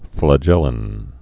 (flə-jĕlĭn)